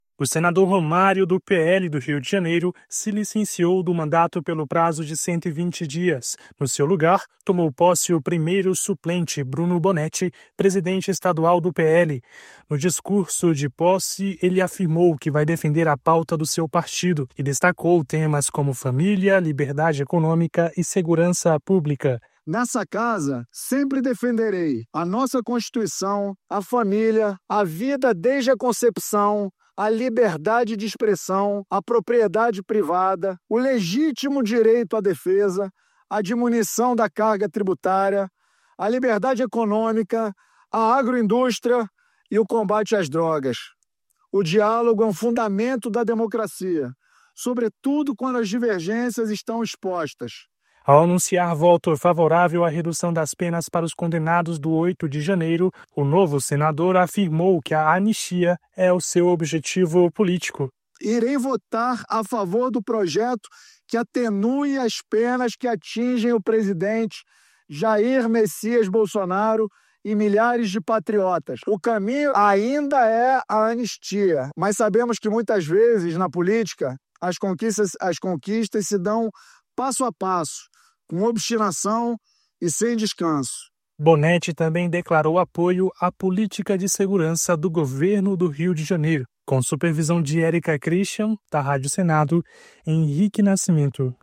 O Senado confirmou a licença de 120 dias do senador Romário (PL-RJ) e deu posse ao suplente Bruno Bonetti, presidente do PL no Rio de Janeiro, que fica no cargo até março. Em seu primeiro discurso no Plenário, ele defendeu pautas conservadoras, criticou o governo federal e anunciou voto favorável a projetos que reduzem penas relacionadas aos atos de 8 de janeiro.